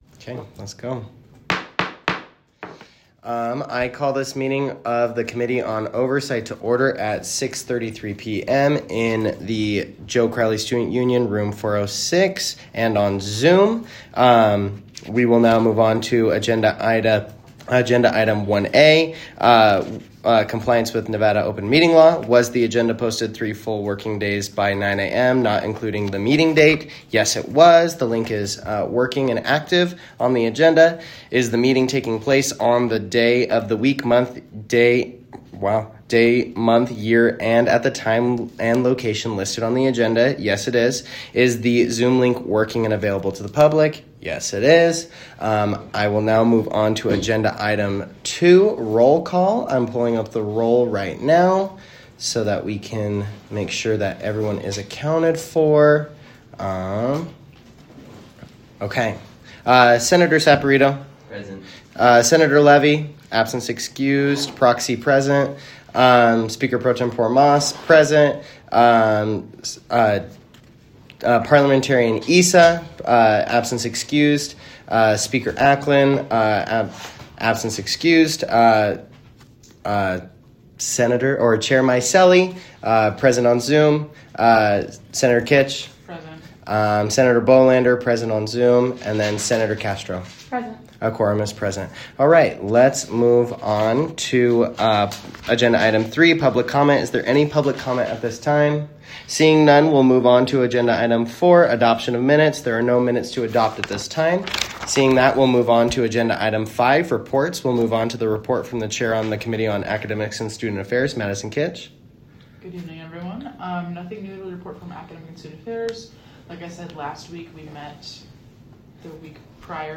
Location : 4th Floor of the Joe Crowley Student Union, Room 406